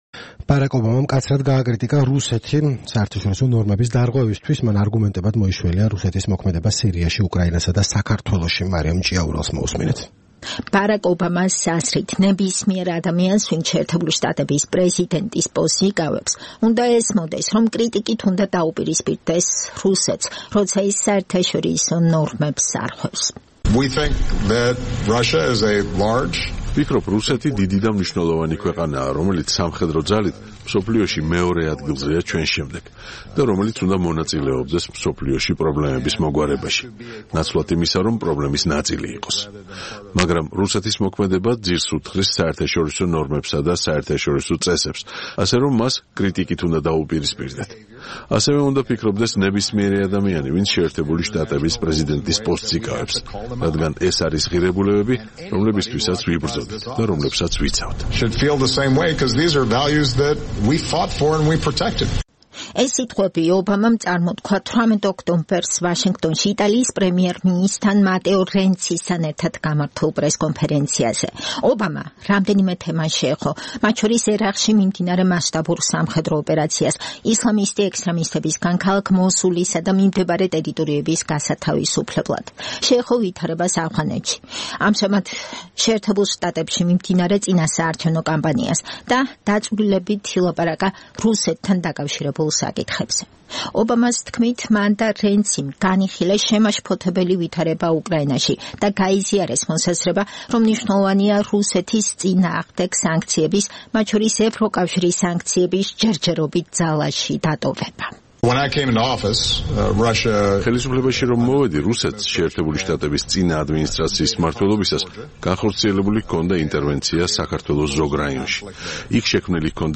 „გამოწვევა, რომლის წინაშეც რუსეთთან მიმართებით ვდგავართ, პირველ რიგში, უკავშირდება რუსეთის აგრესიას მსოფლიოს რამდენიმე რეგიონში. უკრაინაში, სადაც ისინი იმავე სახის მოქმედებას ეწევიან, რაც საქართველოში გააკეთეს - იქაც კი შევეცადეთ შუამავლობას - ევროპელებთან თანამშრომლობით - მინსკის შეთანხმების მისაღწევად, ამ საკითხების მშვიდობიანად მოსაგვარებლად. სირიას რაც შეეხება, პრეზიდენტ პუტინთან ჩემი ერთ-ერთი პირველი შეხვედრის დროს, მას გავუზიარე ვარაუდი, რომ თუ სირიის პრეზიდენტი ბაშარ ალ-ასადი ხელისუფლების სათავეში დარჩებოდა, საკუთარი ხალხის მიმართ მისი სისასტიკის გათვალისწინებით, დაიწყებოდა სამოქალაქო ომი, რაც ნამდვილად არ იქნებოდა კარგი არამარტო სირიელებისათვის, არამედ მსოფლიოსთვისაც. იმის მაგივრად, რომ ჩვენთან ერთად ემუშავა პრობლემის მოსაგვარებლად, მან გააორმაგა ბაშარ ალ-ასადის მხარდაჭერა და ახლა ვიცი, რა ვითარებაც არის იქ“, - განაცხადა ბარაკ ობამამ ვაშინგტონში 18 ოქტომბერს გამართულ პრესკონფრენციაზე.